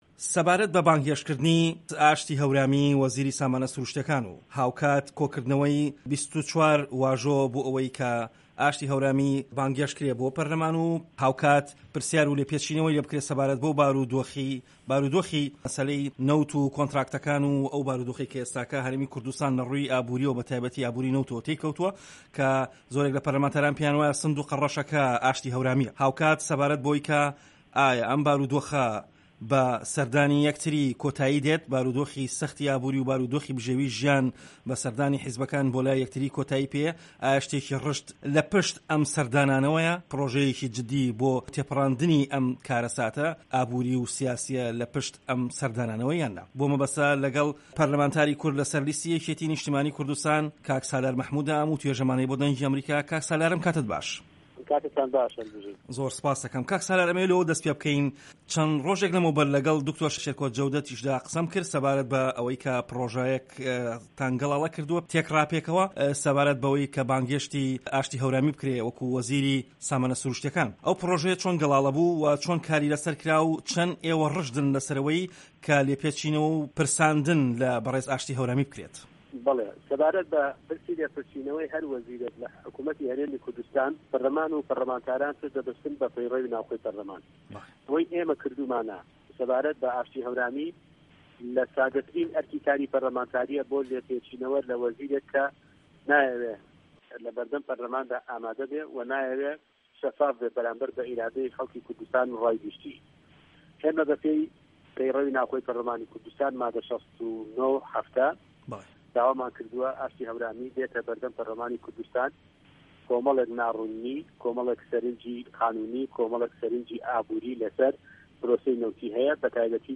وتوێژ لەگەڵ سالار مەحمود